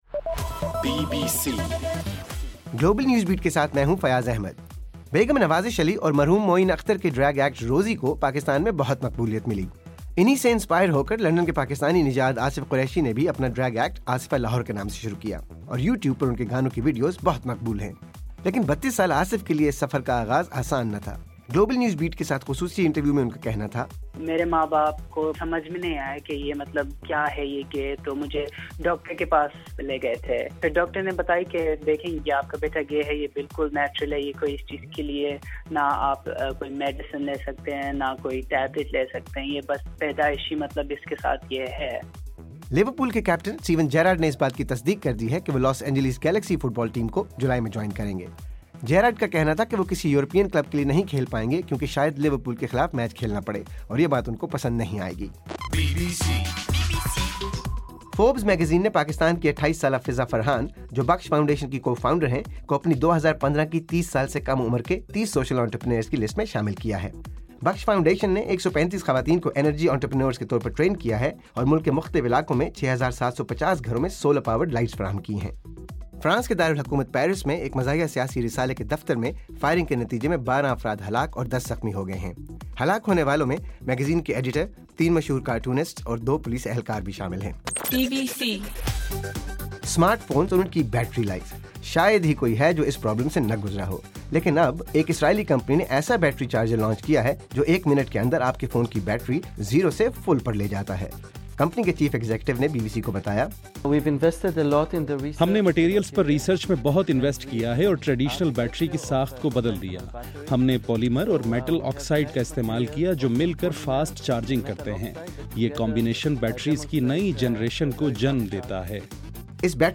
جنوری 07: رات 12 بجے کا گلوبل نیوز بیٹ بُلیٹن